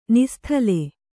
♪ nisthale